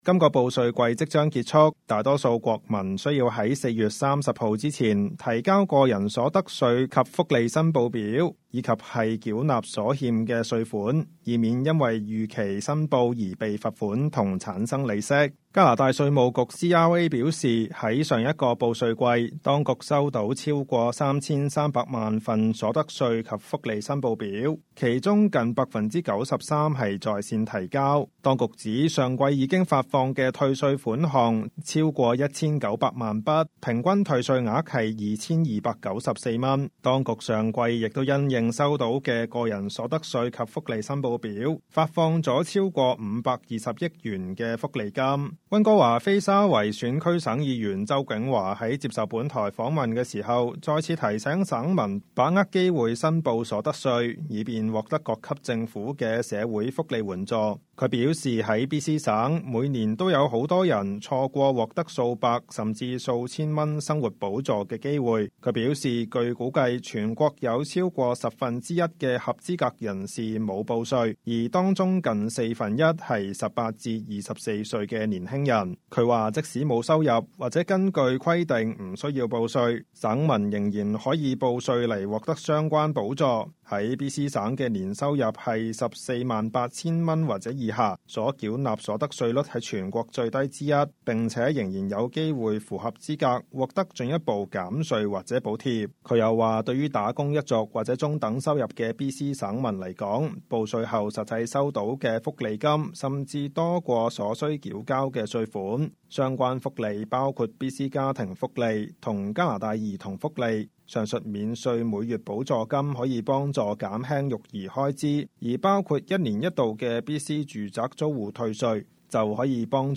Local News 本地新聞
溫哥華─菲沙圍 (Vancouver-Fraserview) 選區省議員周烱華在接受本台訪問時，再次提醒省民把握機會申報所得稅，以便獲得各級政府的社會福利援助。